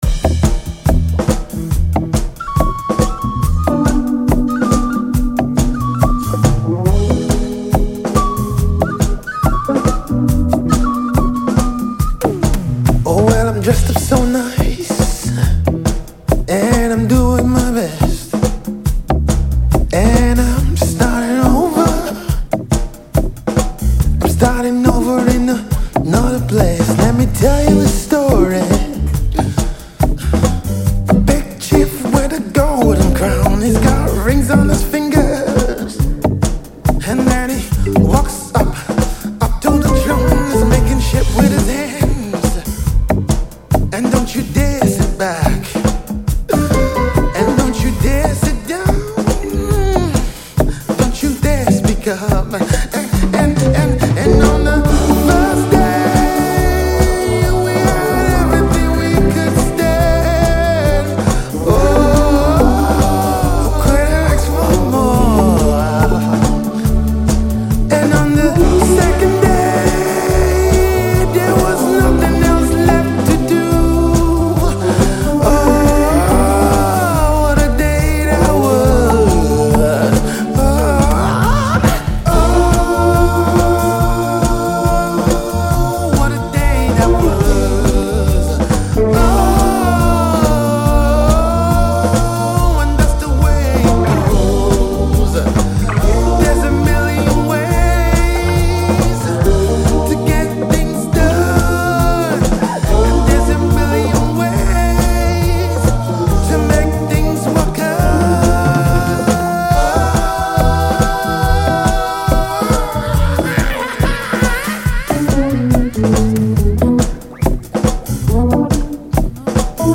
Prominent Nigerian Highlife Duo